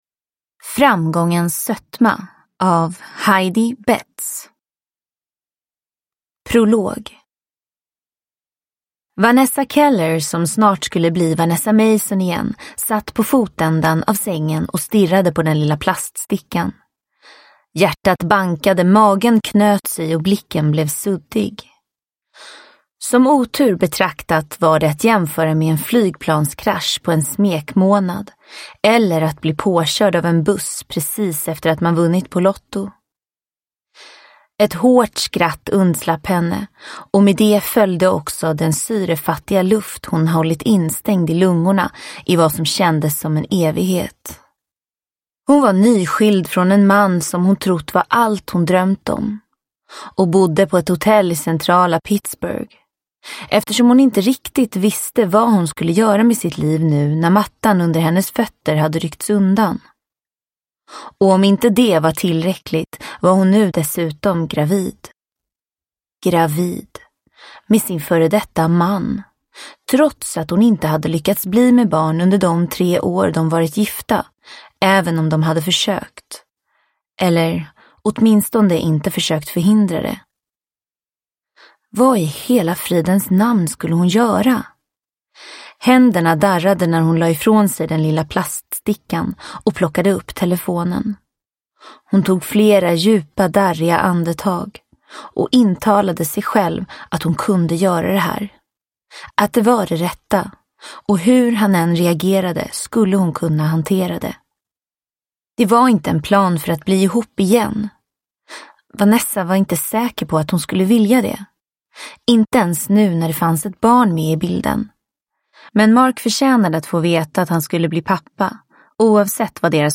Framgångens sötma – Ljudbok – Laddas ner